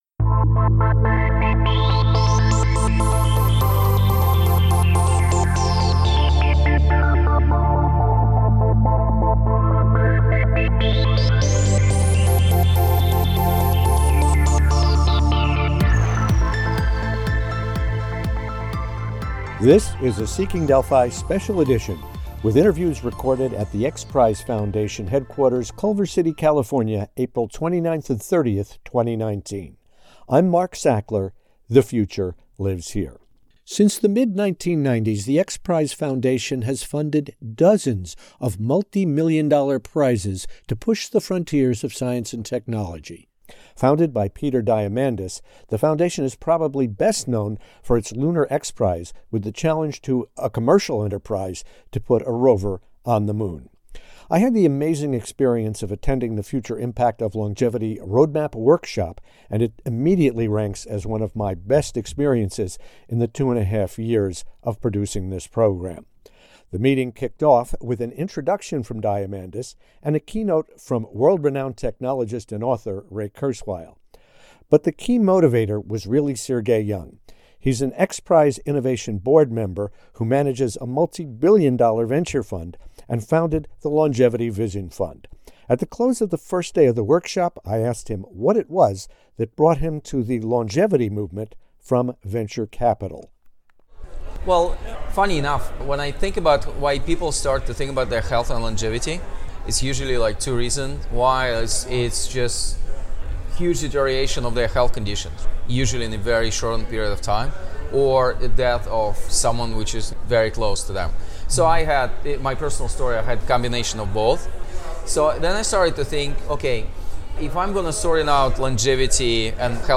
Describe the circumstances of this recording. This Seeking Delphi™ special edition podcast features interviews and commentary from the workshop.